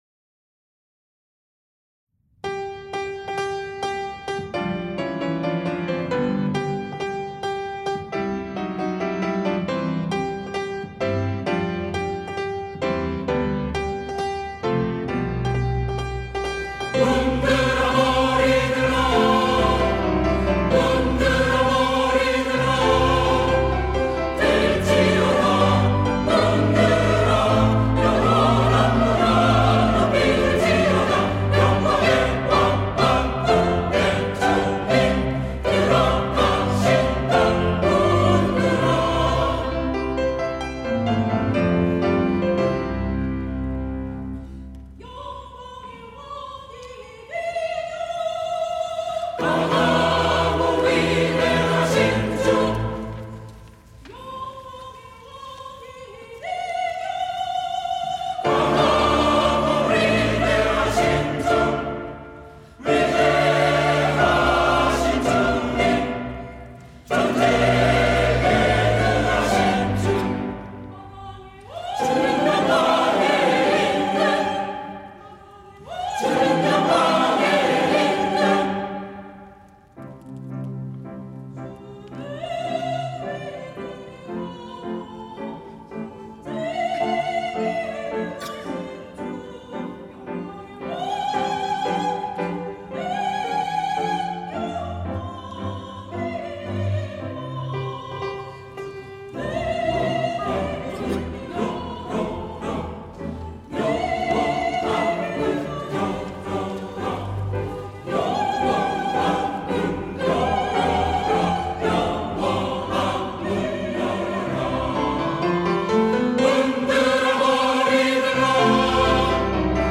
시온(주일1부) - 문들아 머리 들라
찬양대